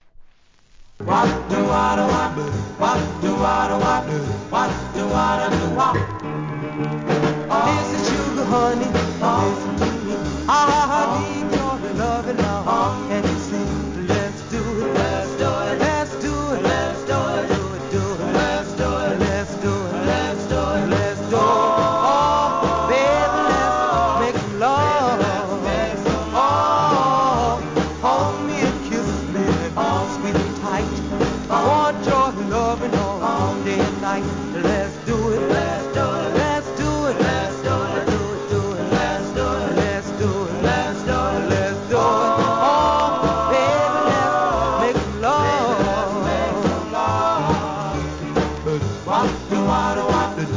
店舗 数量 カートに入れる お気に入りに追加 DOO WOP!!